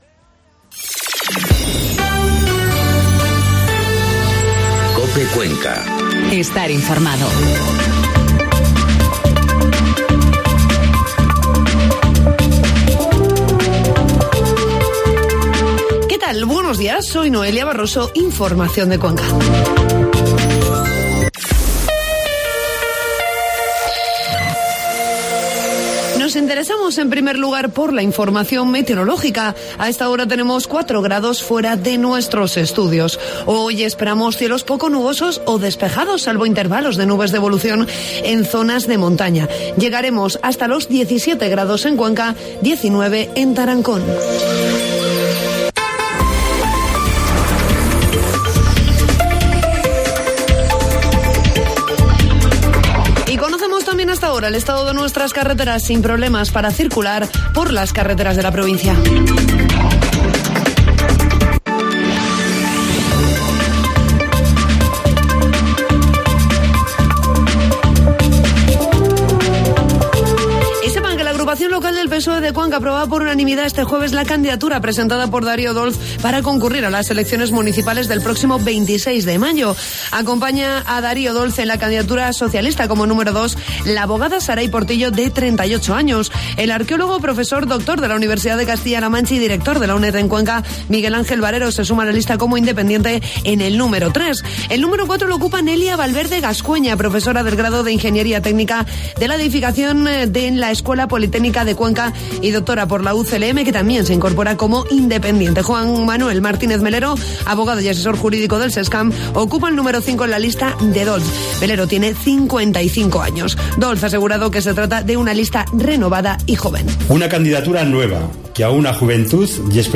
Informativo matinal COPE Cuenca 29 de marzo